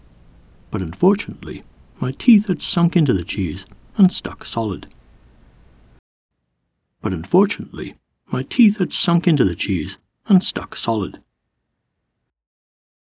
Noise Reduction remnants.
I THINK it is a remnant of the noise reduction process. At the end of almost every sentence, I have a little extra sound.
There is something wrong with the first word or two??? Everything else sounds OK.
You are overdoing your noise reduction and/or under-doing your studio noise suppression. I count at least two different vent fans running in the background and there may be more individual noises I couldn’t identify.
The background noise in the raw sample came in about -50dB, significantly louder than the AudioBook standard of -60dB.